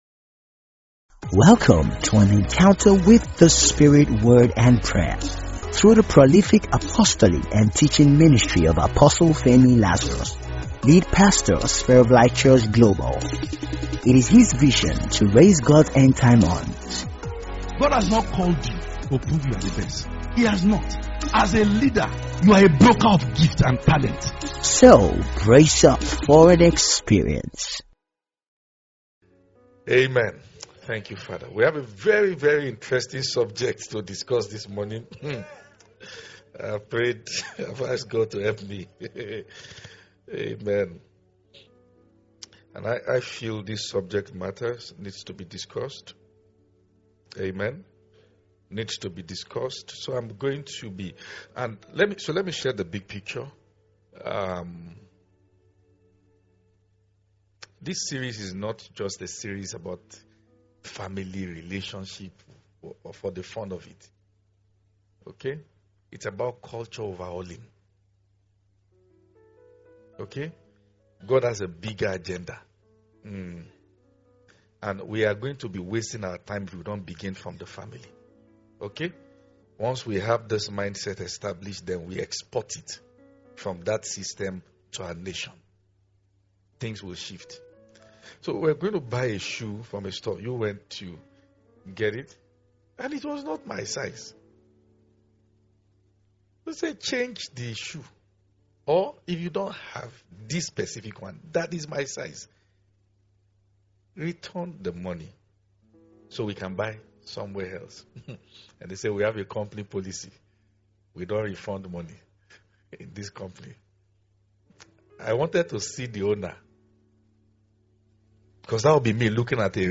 it’s may be a curse to you if you are going about it without wisdom..In this powerful message